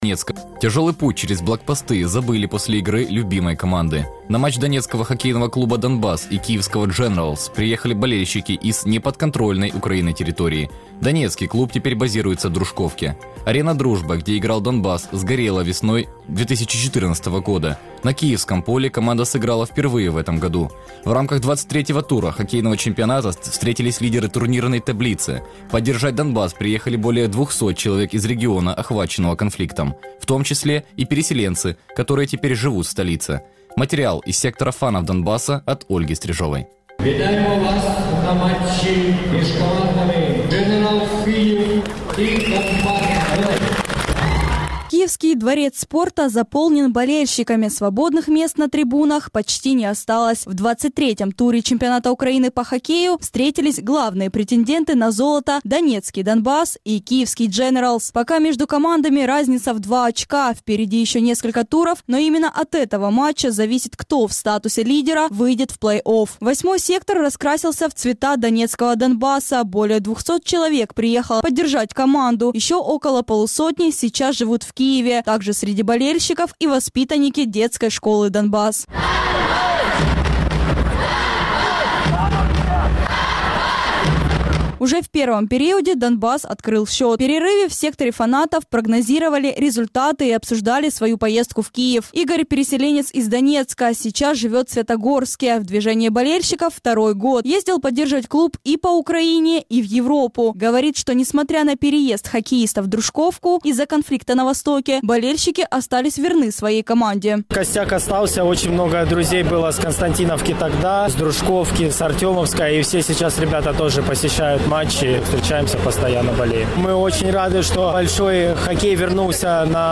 У перерві в секторі фанатів прогнозували результати і обговорювали свою поїздку до Києва